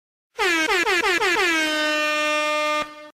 Download Rap Airhorns sound effect for free.
Rap Airhorns